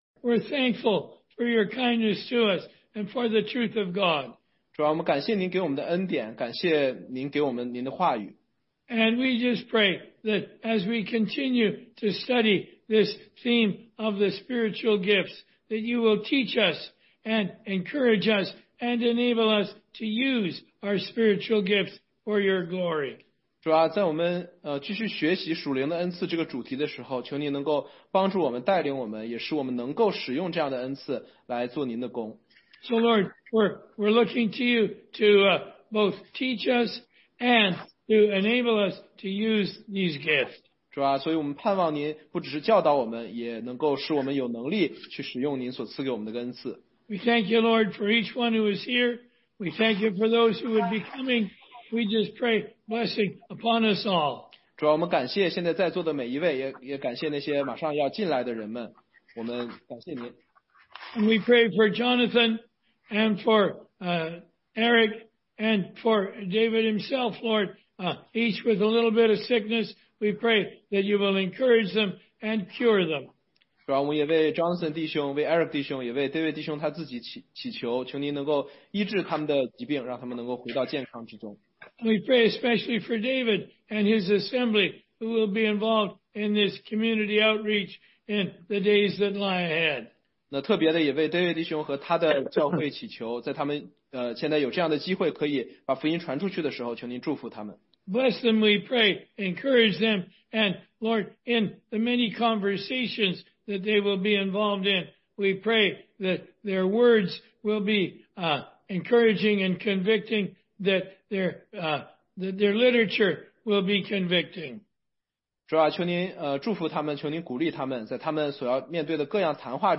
16街讲道录音 - 中英文查经